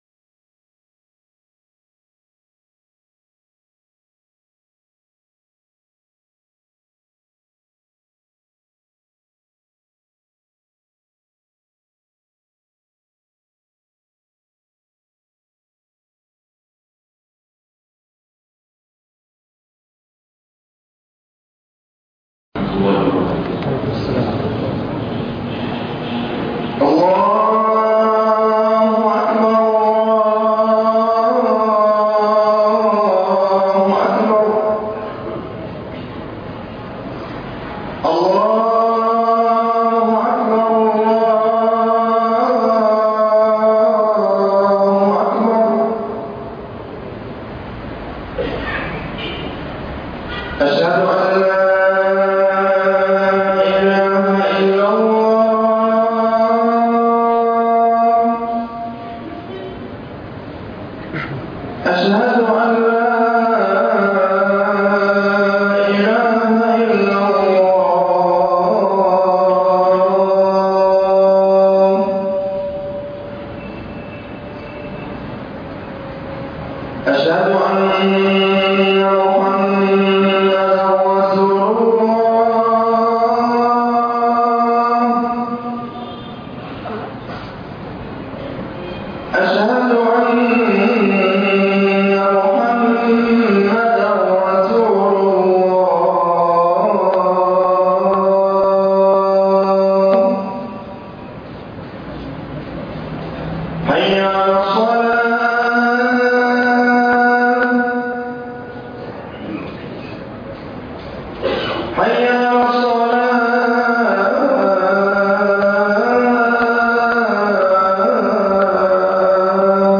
ماذا بعد رمضان ؟ خطبة قوية جدًااا